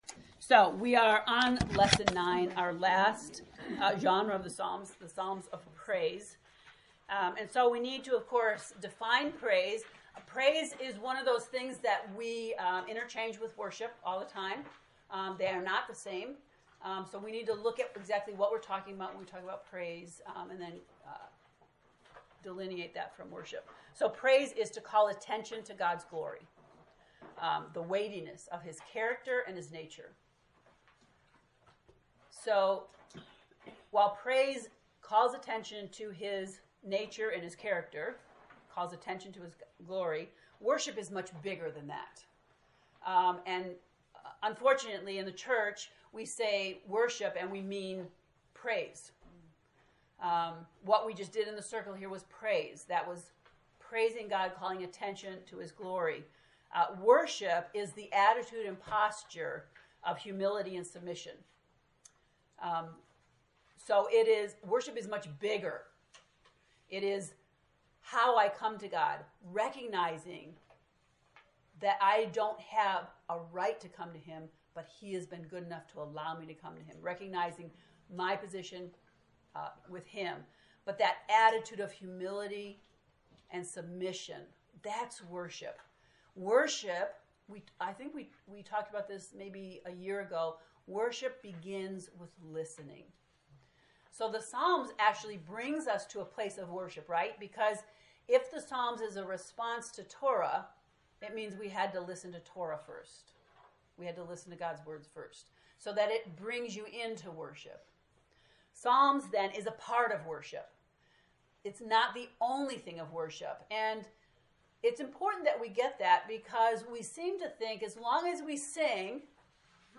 To listen to the lesson 9 lecture, “Psalms of Praise,” click here: